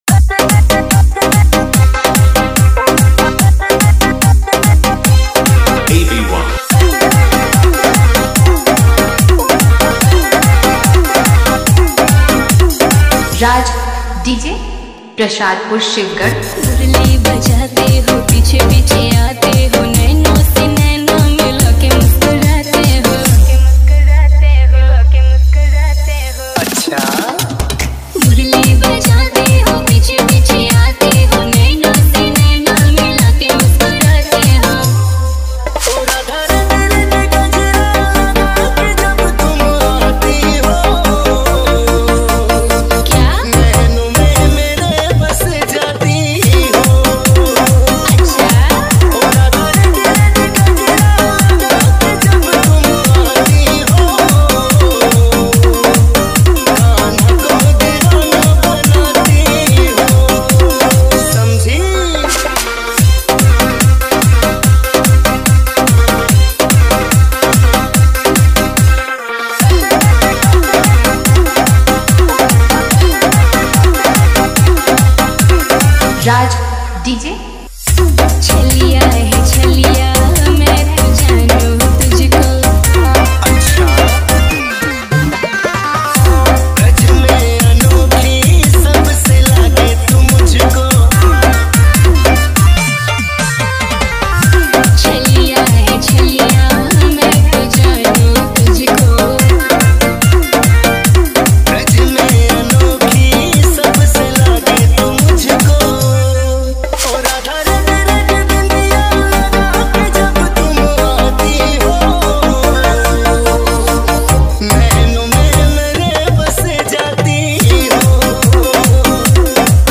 Radha Krishna Dj Song, Bhakti Remix Song
Competition Dj Remix